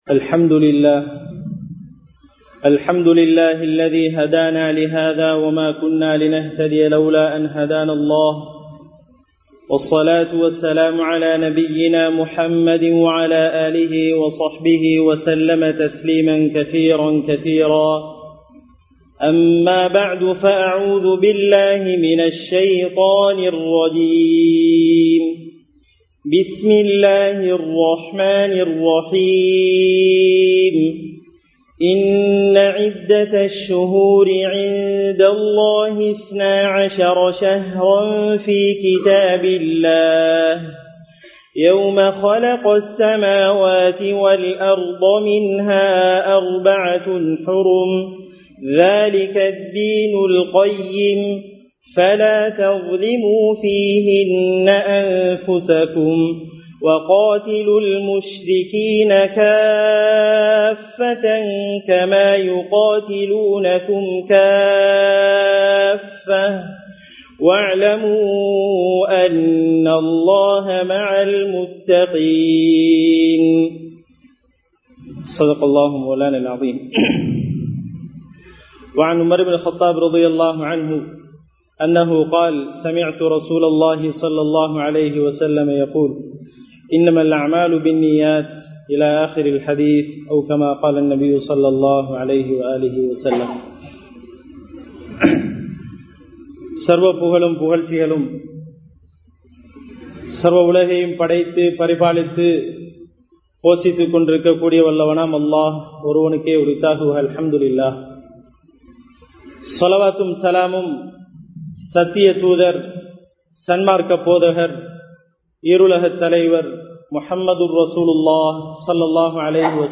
மன்னியுங்கள் விட்டுக் கொடுங்கள் (Forgive and forget) | Audio Bayans | All Ceylon Muslim Youth Community | Addalaichenai